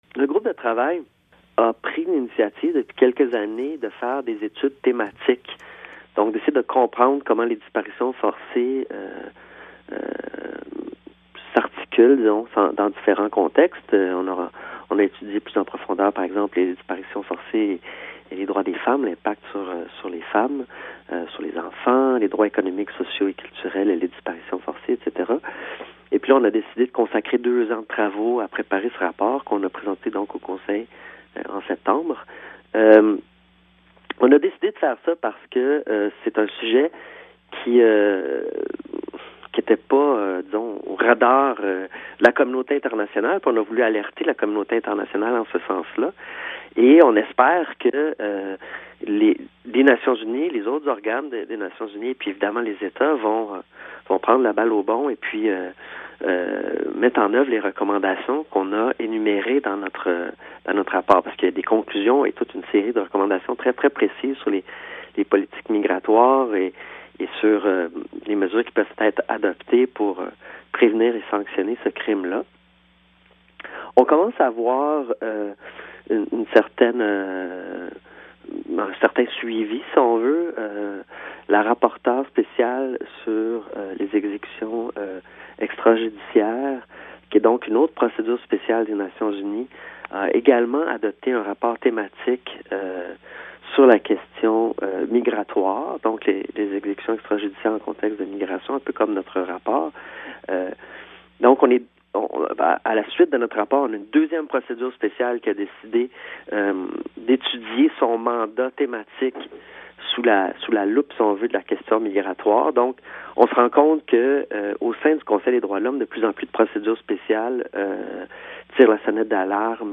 Il nous parle de ce phénomène difficile à documenter et il explique dans cet extrait pourquoi c’est urgent que la communauté internationale agisse.